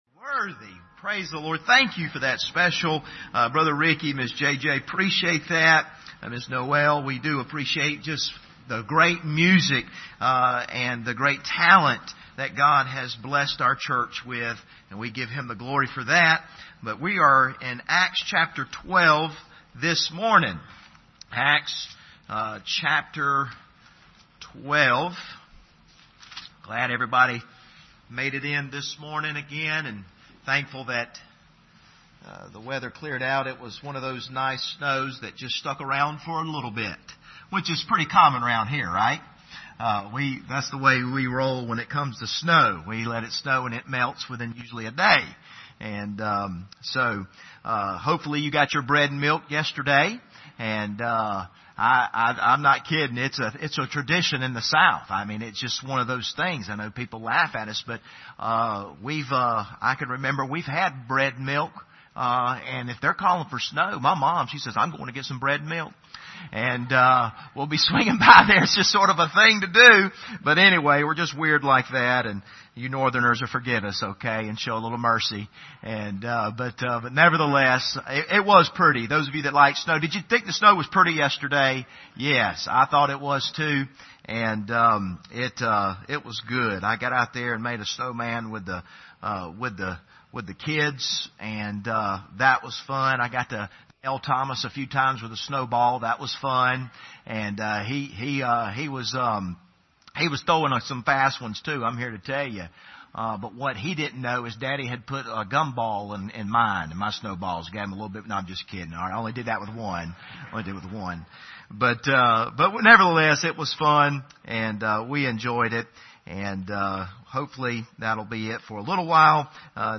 Passage: Acts 12:20-23 Service Type: Sunday Morning